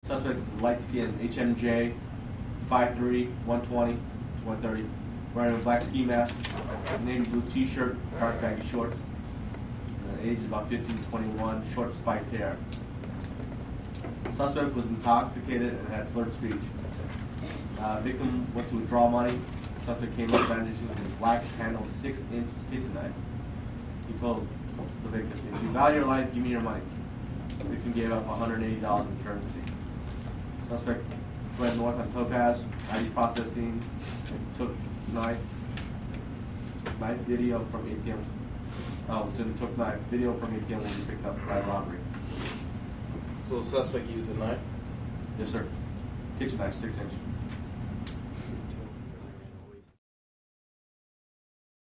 Police Briefing: Man Talking.